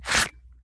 Leather Unholster 002.wav